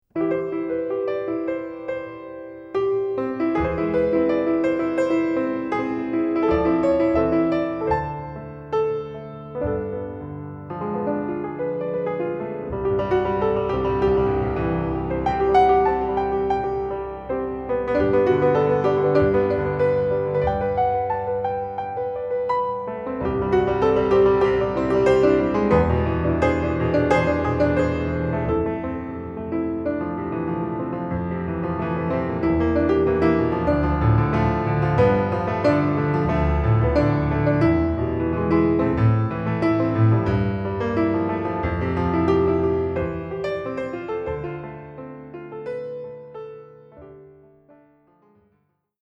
Broadway